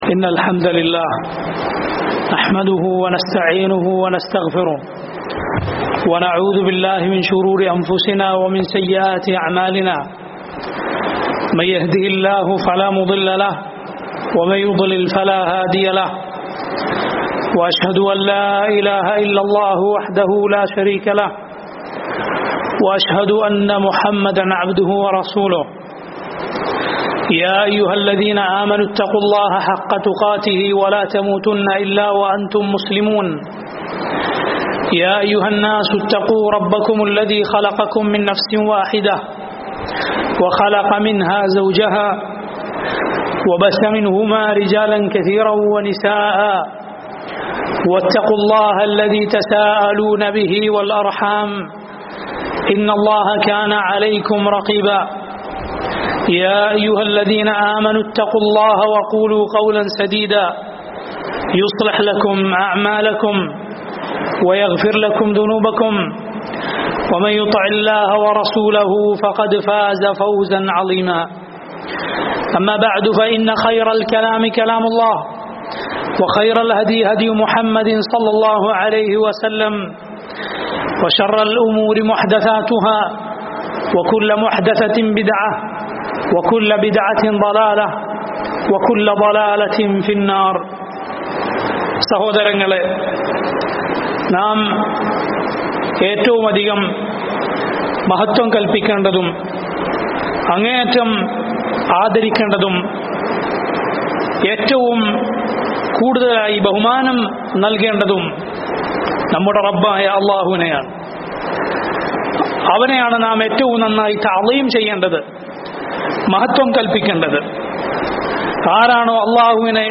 ജുമുഅ ഖുത്ബ // 22 സഫർ 1442 // കണ്ണൂർ സിറ്റി സലഫി മസ്ജിദ്